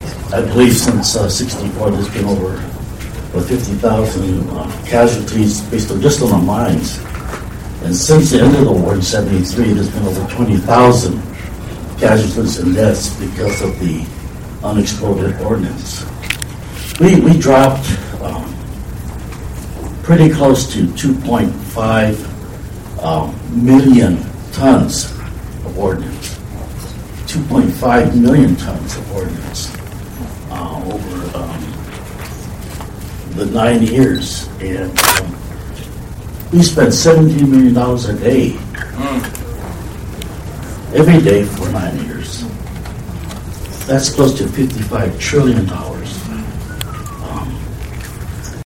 ທ່ານ Mike Honda ຜູ້ແທນສະພາຕຳ່ ສະຫະລັດ
ນັ້ນແມ່ນການສຳພາດ ບັນດາຄະນະບໍລິຫານ ແລະອາສາສະມັກຂອງ ອົງການມໍລະດົກຫລັງສົງຄາມ ໃນງານສະຫລອງຄົບຮອບ 10 ປີຂອງການປະຕິບັດງານຊ່ວຍເກັບກູ້ລະເບີດ ຢູ່ໃນ ສປປ ລາວ.